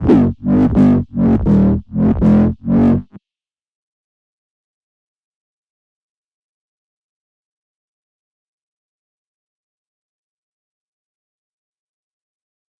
摇摆不定的贝司声 " 贝司重采样7
描述：音乐制作的疯狂低音
Tag: 重采样 重低音 音效设计 摇晃